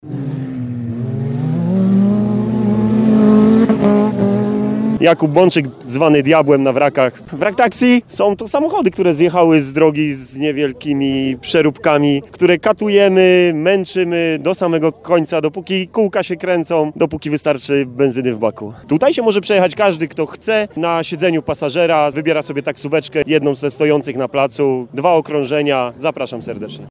Ryk silników, pisk opon i maszyny takie, że dech zapiera.
Nieco dalej od hali ulokowały się atrakcje terenowe, wśród nich wrak taxi.